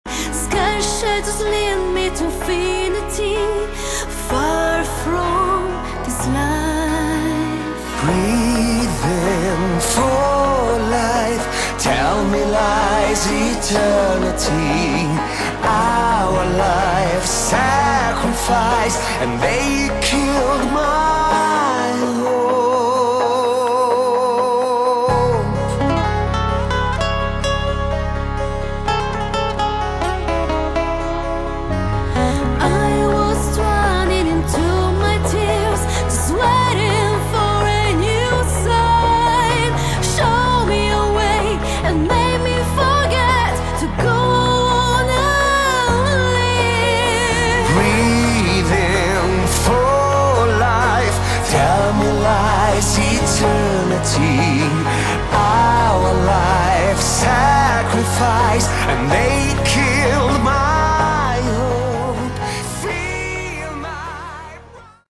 Category: Melodic Metal
lead vocals
guitars, keyboards
bass
drums